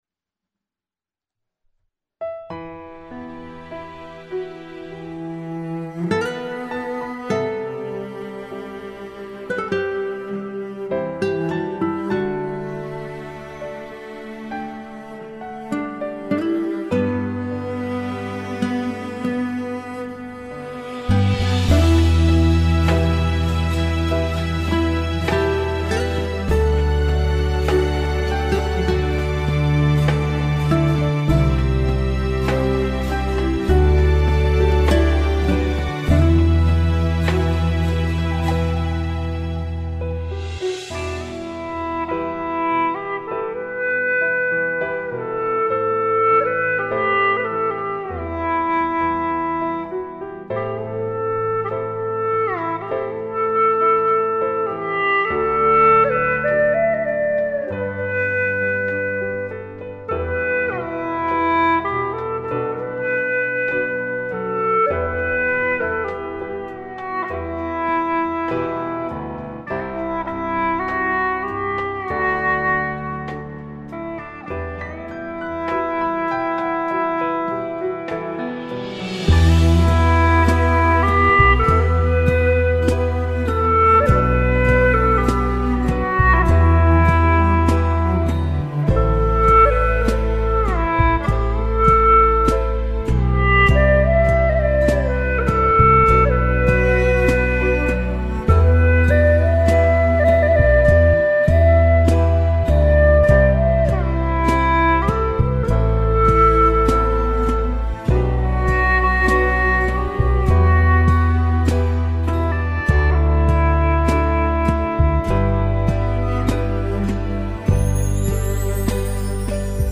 调式 : G